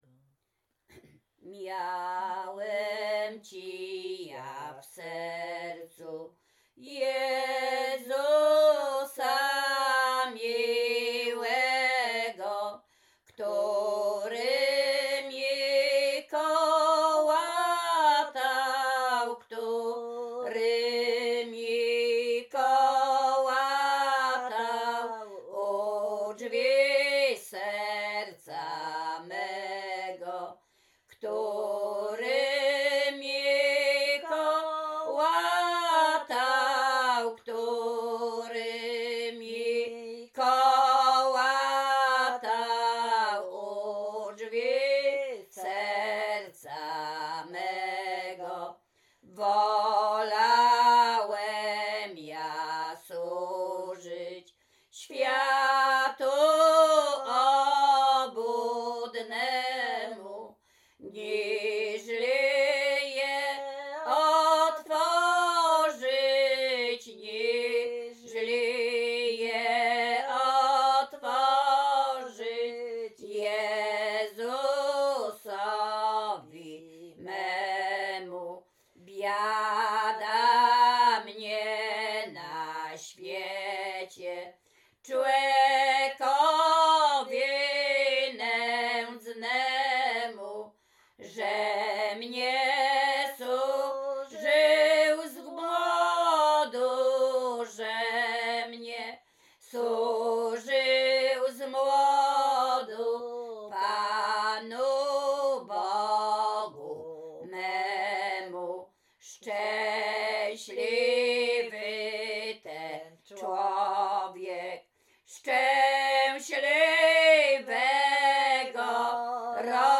Śpiewaczki z Czerchowa
Łęczyckie
Pogrzebowa
Array nabożne katolickie pogrzebowe